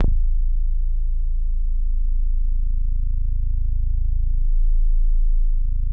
WAVEBASS  A1.wav